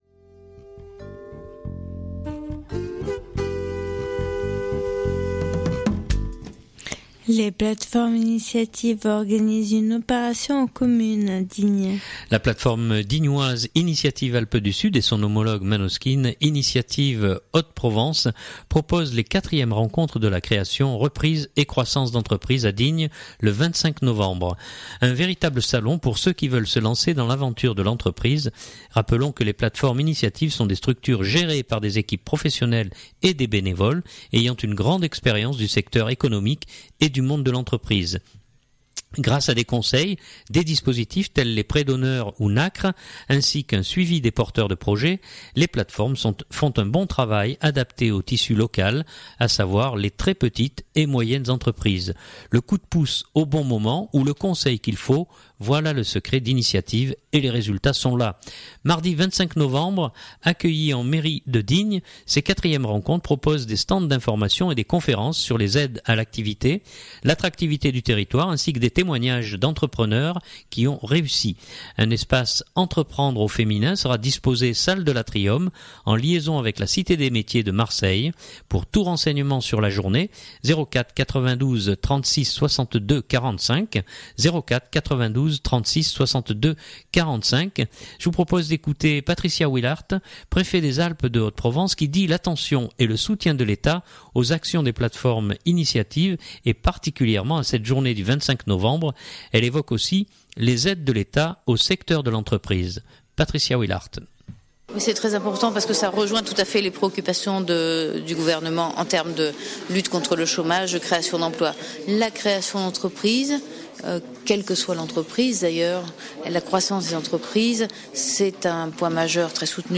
Je vous propose d’écouter Patricia Willaert, Préfet des Alpes de Haute-Provence qui dit l’attention et le soutien de l’Etat aux actions des plateformes Initiative et particulièrement à cette journée du 25 novembre. Elle évoque aussi les aides de l’Etat au secteur de l’entreprise.